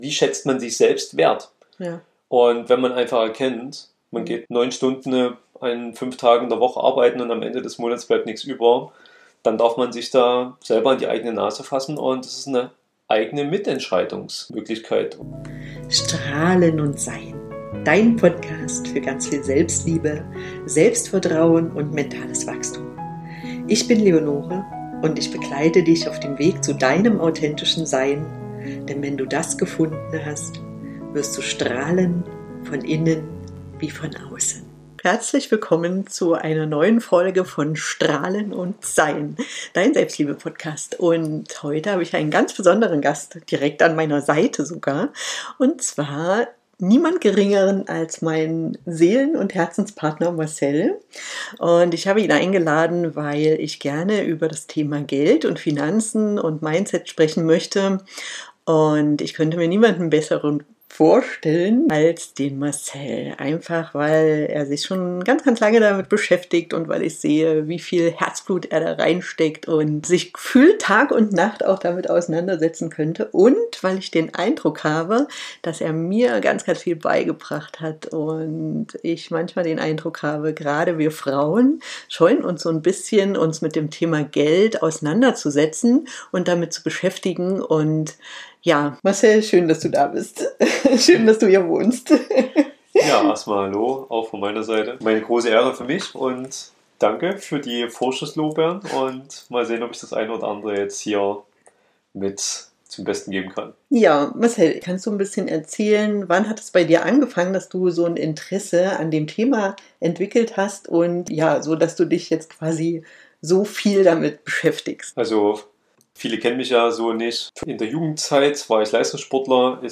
Wir teilen mit dir unsere ganz eigenen Wege im Umgang mit Geld – erst jeder für sich, dann gemeinsam als Paar. Offen, ehrlich und mit einem Augenzwinkern sprechen wir über: